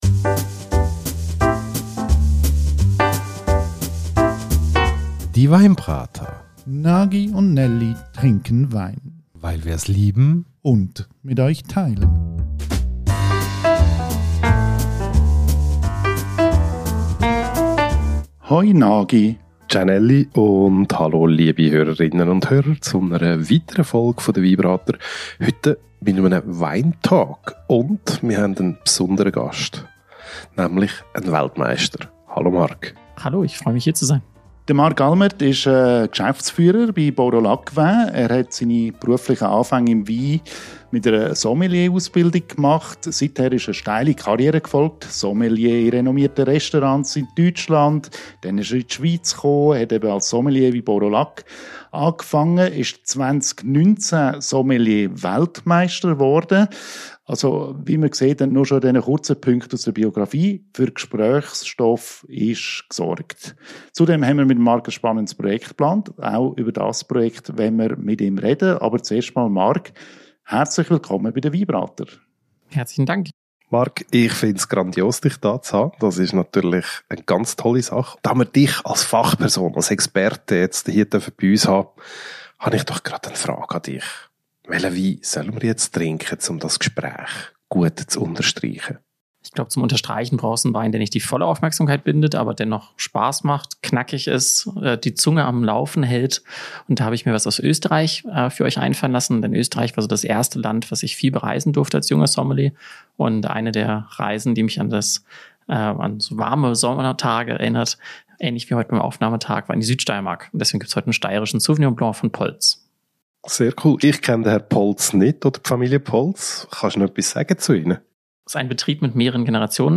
Wieder mal eine Premiere bei den Weinbratern: Zum ersten Mal schaut ein Sommelier bei uns vorbei.